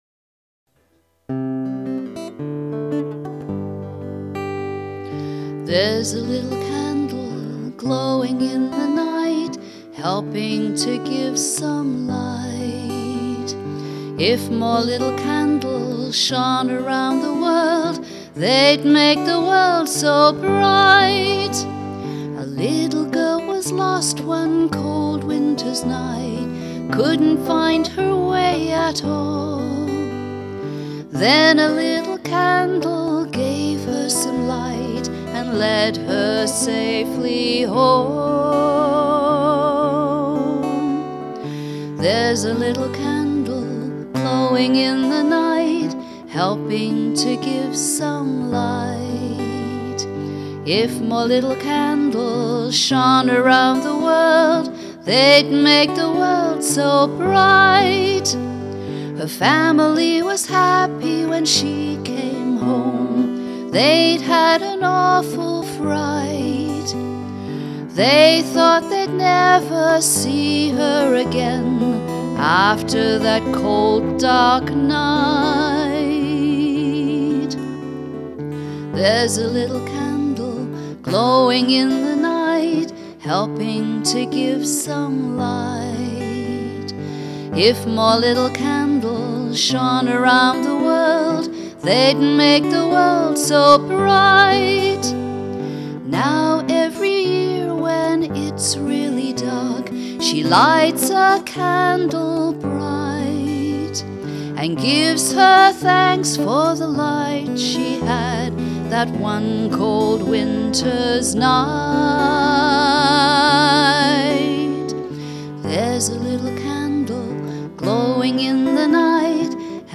vocals and guitar